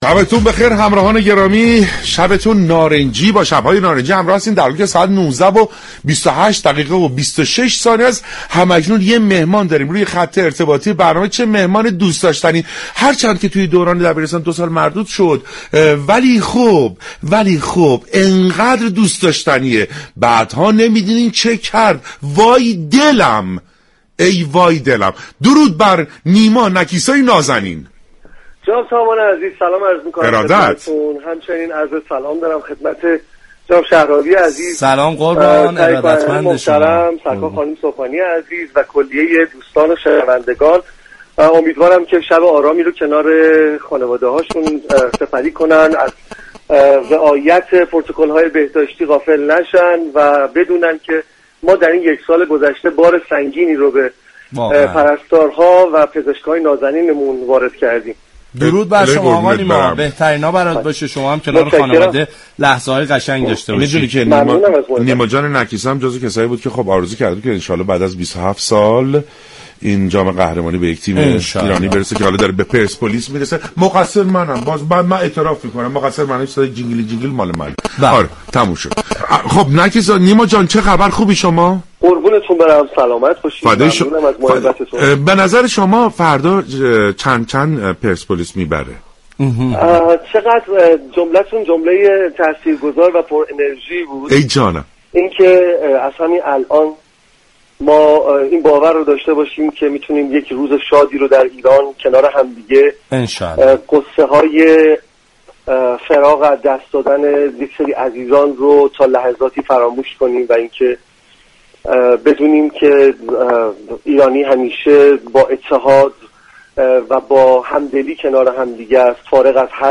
مژده نیما نكیسا در گفتگو با رادیو ورزش